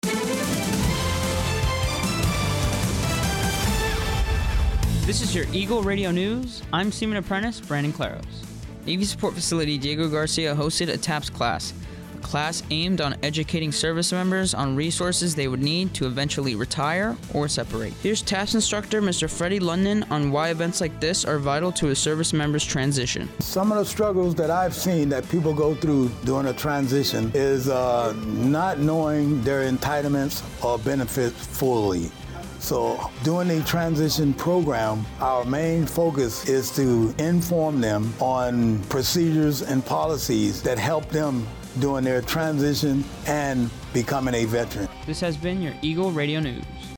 Eagle Radio News is the American Forces Network Diego Garcia’s official radio newscast.